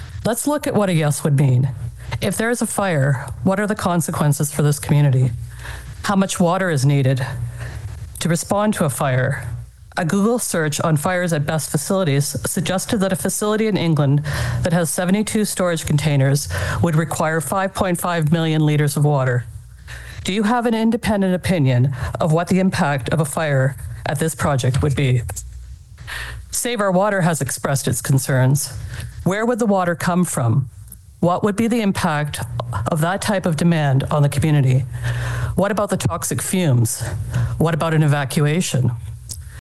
spoke at the meeting last night and said there are just too many unanswered questions.